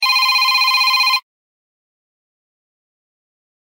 • Качество: 129, Stereo
Стандартный рингтон